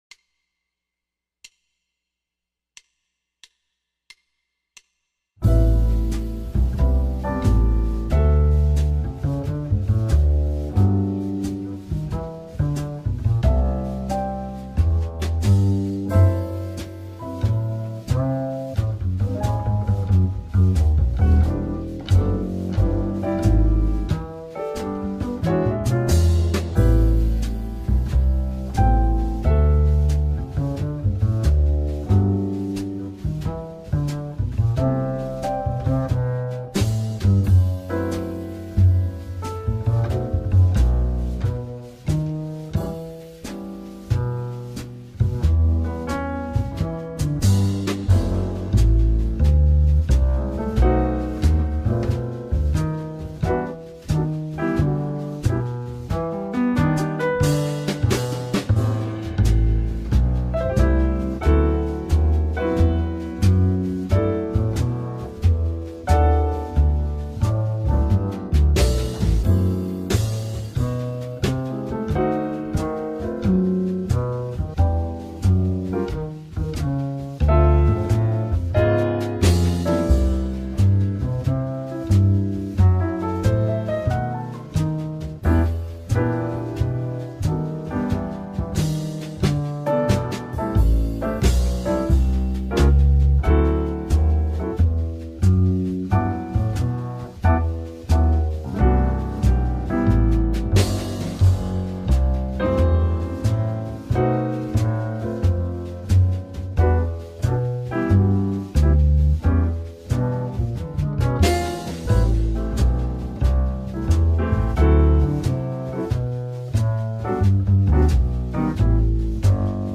Autumn Leaves Playalong
Autumn-Leaves-slow-Backing-track-Play-along.mp3